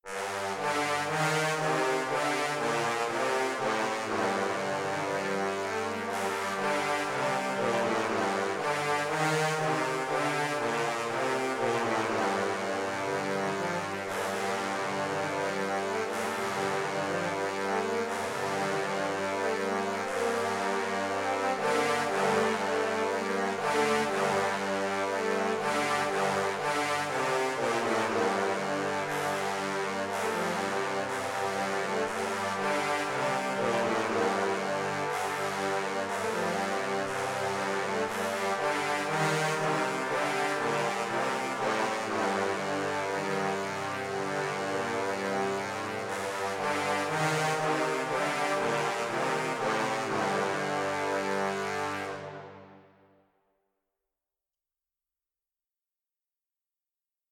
Enthalten sind 3 Tenor, 2 Bass und 1 Kontrabass Trombonen.